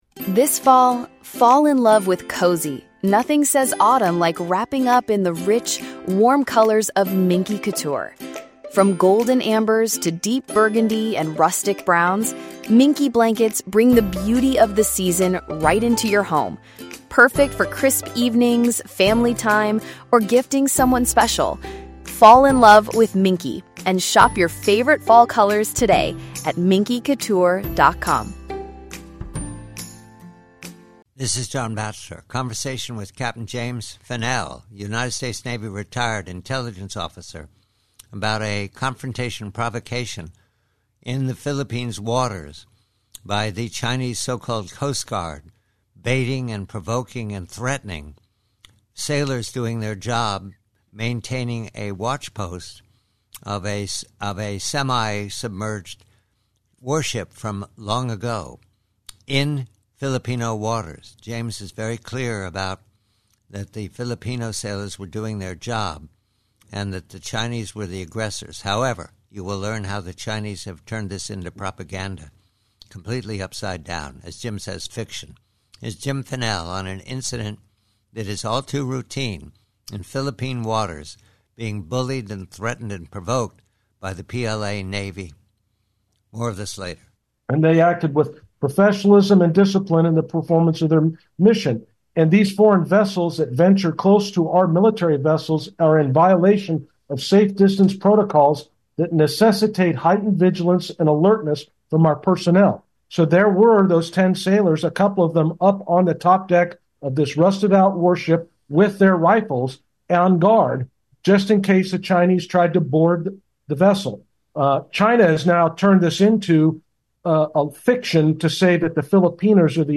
PREVEW: SOUTH CHINA SEA: PRC: Conversation